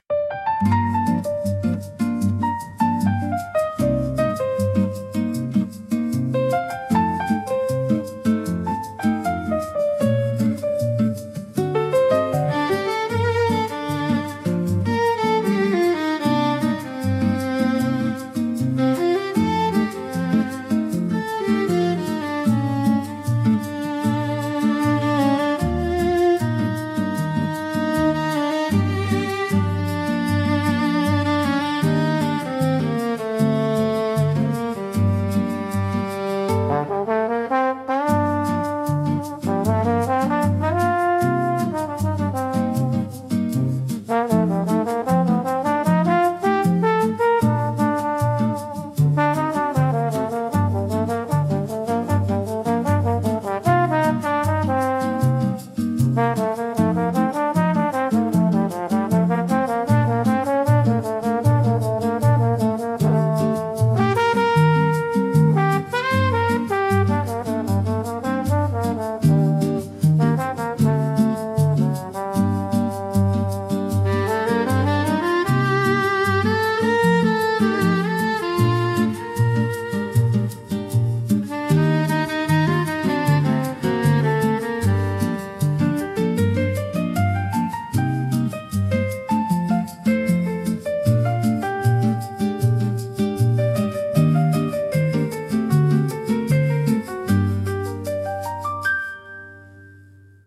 música e arranjo: IA) instrumental 5